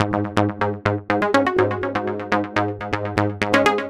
Prog Element Ab 123.wav